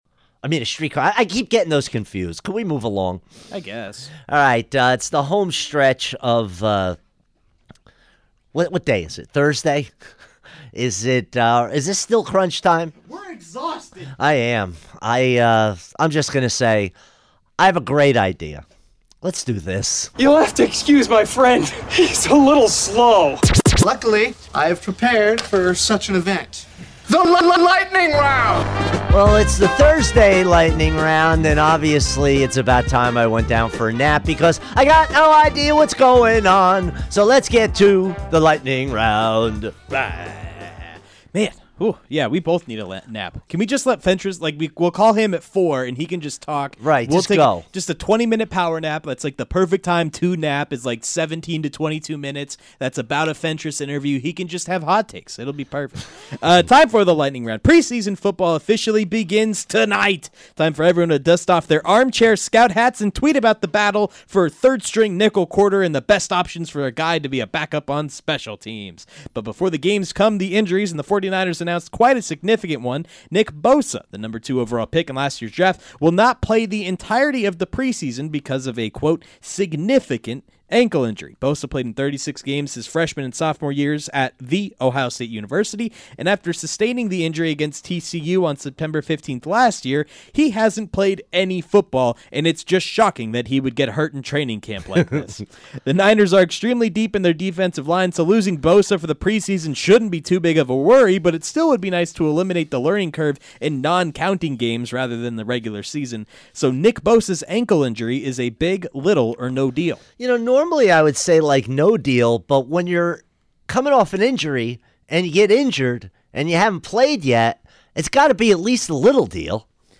fires through the biggest sports stories of the day, rapid-fire style